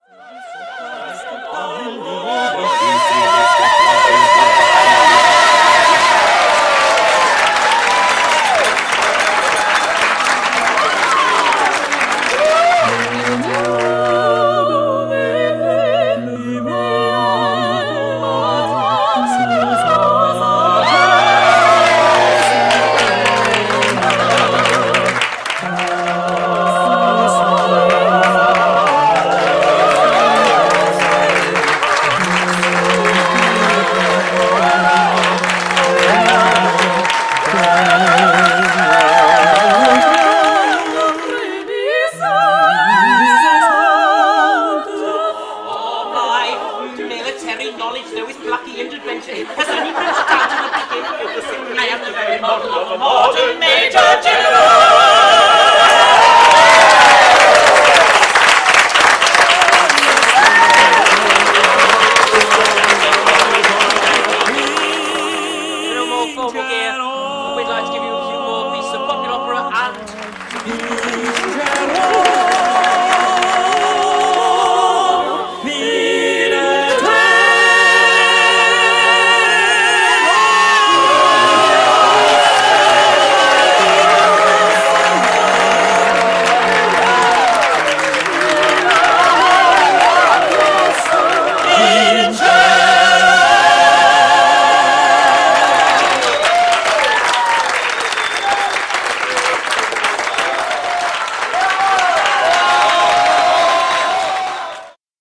Classically trained vocalists in disguise!